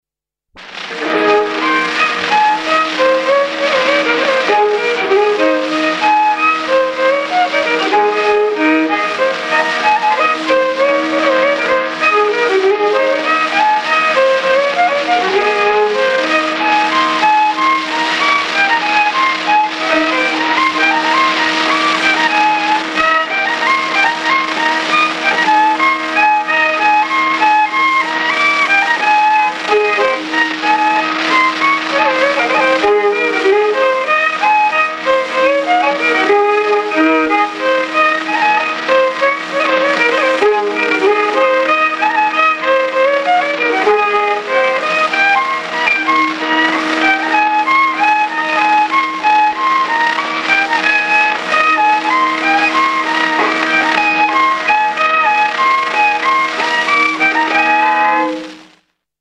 I viiul
burdoonsaade
05 Labajalg.mp3